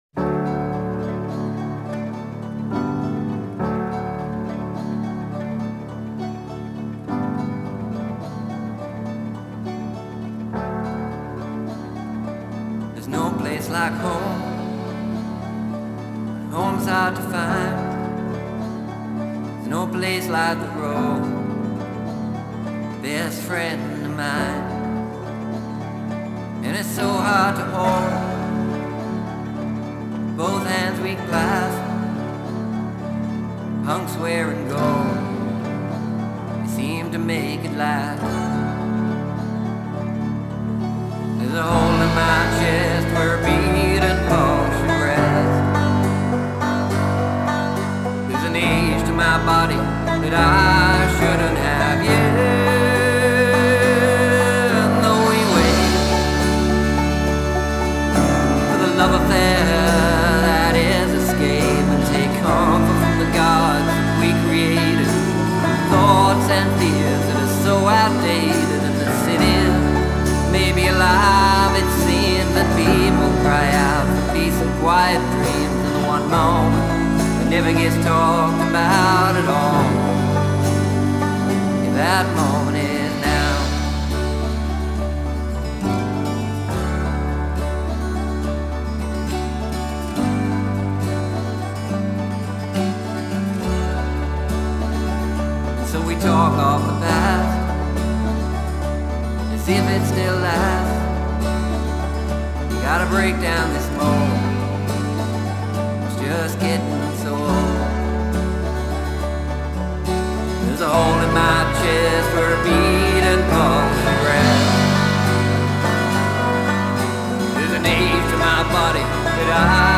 Genre: Folk, Roots, Alternative